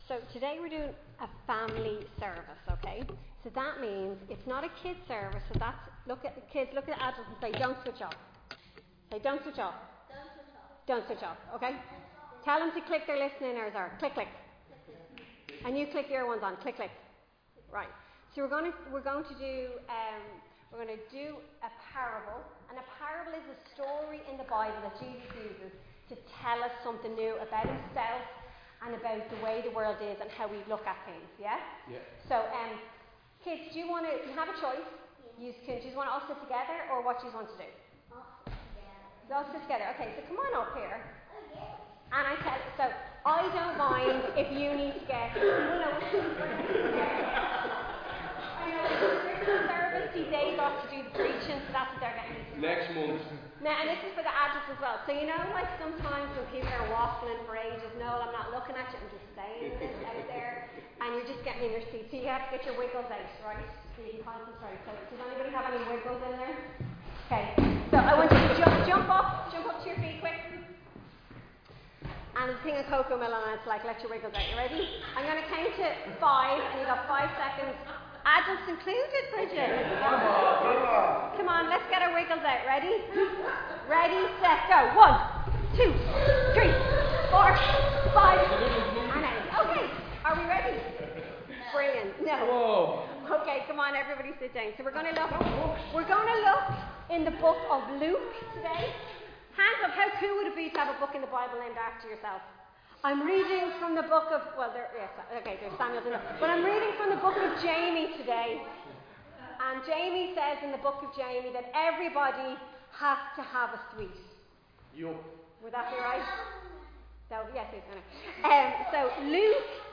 Recorded live in Liberty Church on 27 April 2025